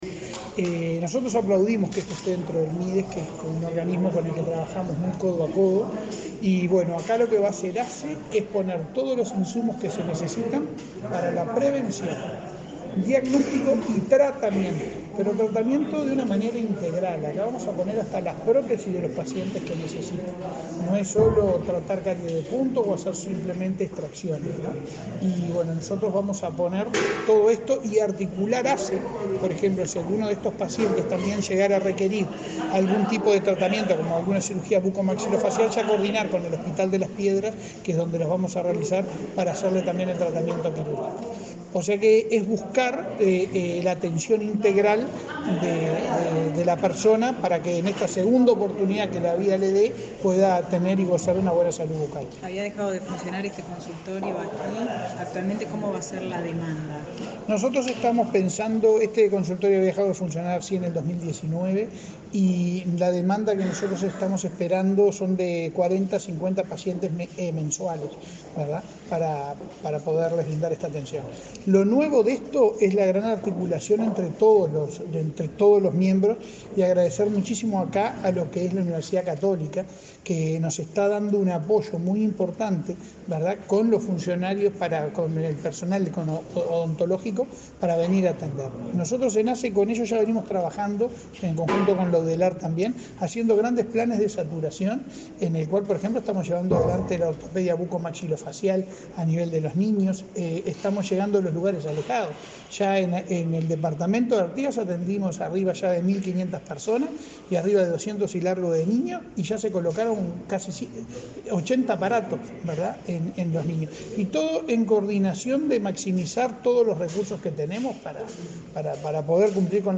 Declaraciones del presidente de ASSE a la prensa